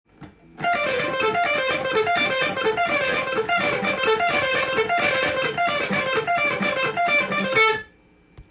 エレキギターで弾ける【ランディーローズフレーズ集】tab譜
フレーズ集は、全てDm　keyで使用できるものになっています。
③のフレーズも６連符ですが規則性があるようで
微妙に変化をつけています。